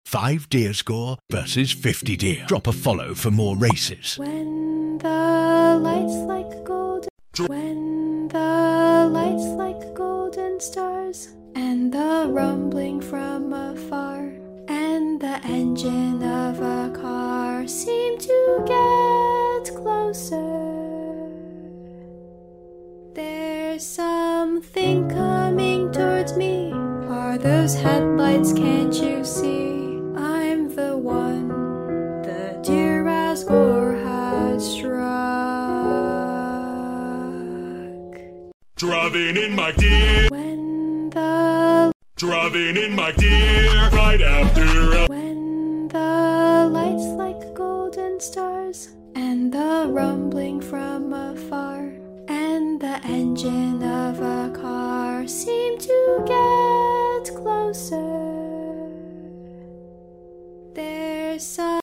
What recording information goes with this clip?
- Recorded & edited by me with added effects, overlays & commentary for entertainment.